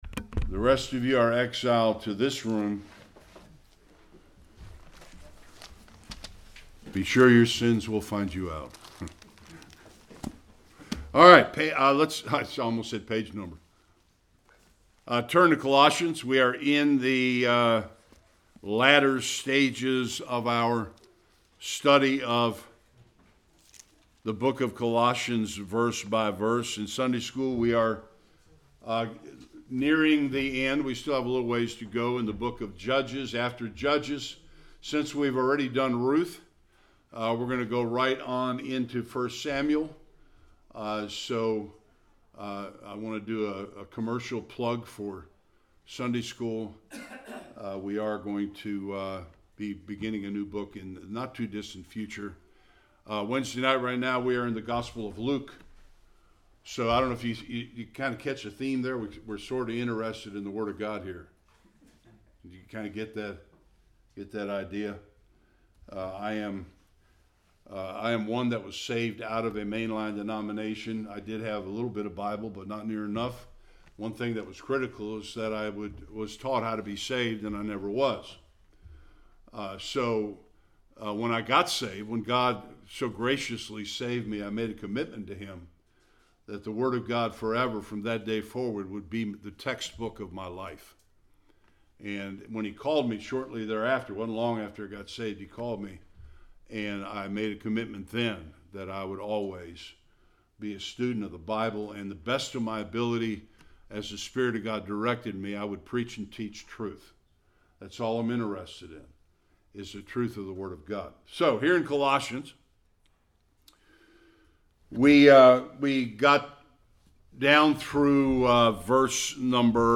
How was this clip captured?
Colossians 3:20-4:1 Service Type: Sunday Worship Paul continues to deal with how to have a Godly home.